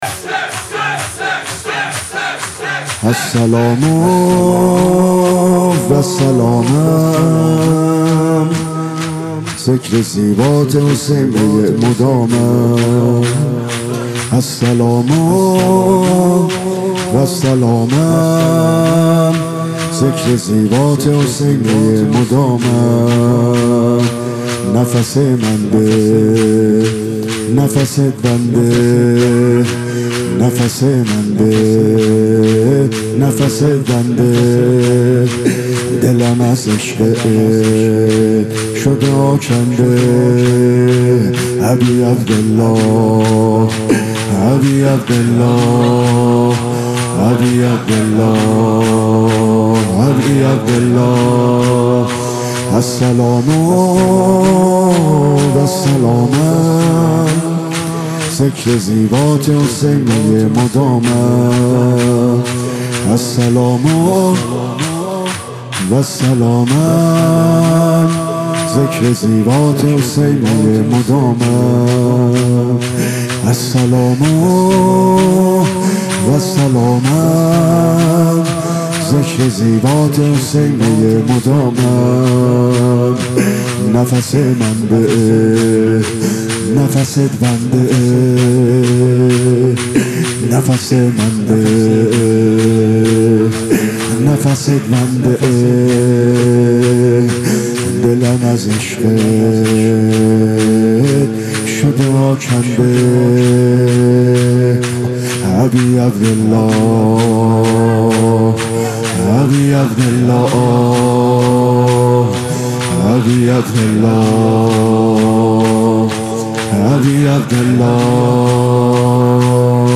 دهه اول محرم الحرام ۱۴۰۱ در هیئت الرضا(محفل بسیجیان و رهروان شهدا) با نوای حاج عبدالرضا هلالی در مجموعهٔ فرهنگی شهدای انقلاب در میدان بهارستان برگزار می گردد.
مداحی